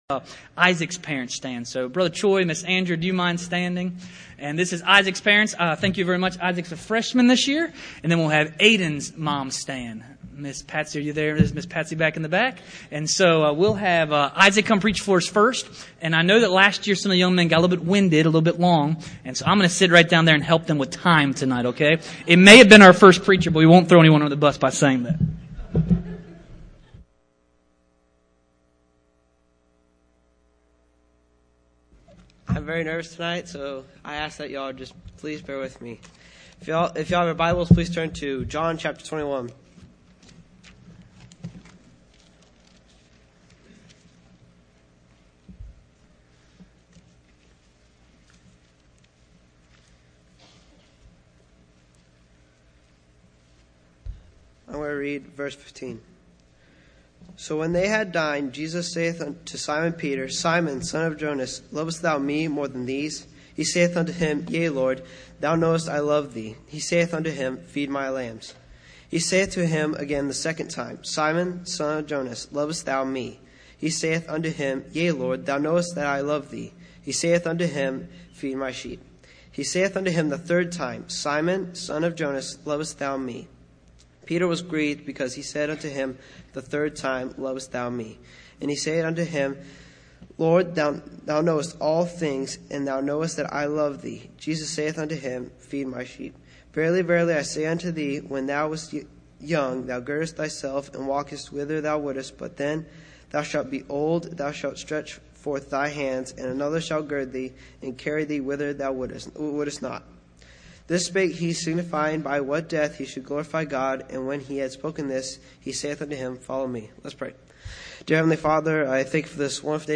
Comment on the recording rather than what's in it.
Teen Service